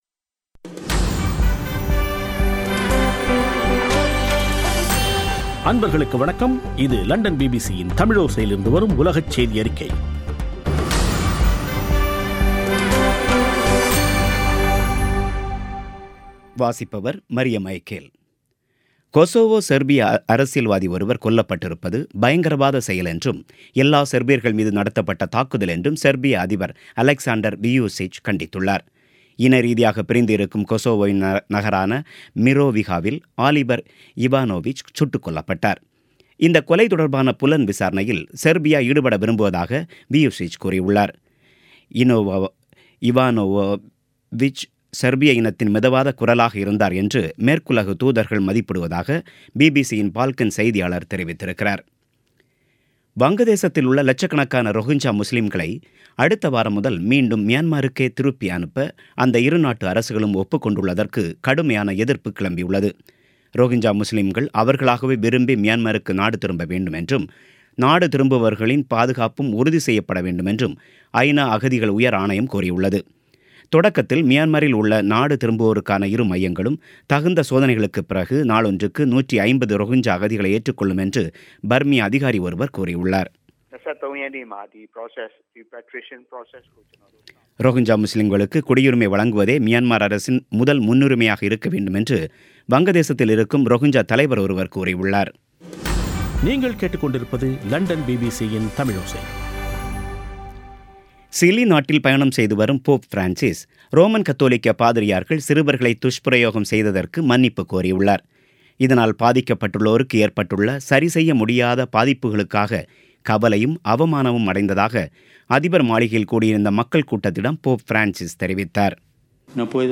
பிபிசி தமிழோசை செய்தியறிக்கை (16/01/2018)